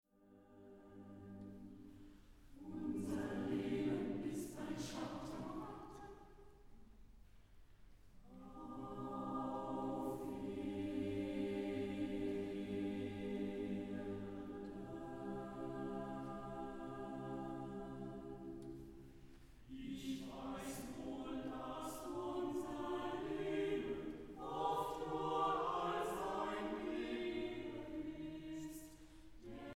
Festliches Konzert zu Ostern